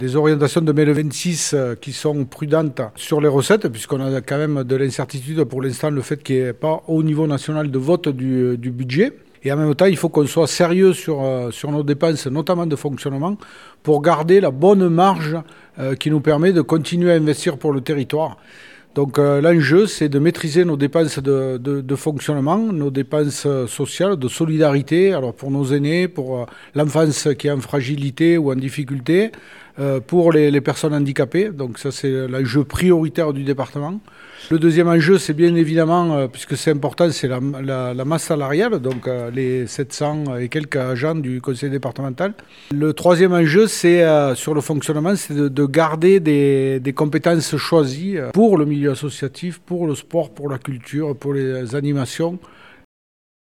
Le président du Conseil départemental, Laurent Suau, détaille les enjeux de ce budget 2026.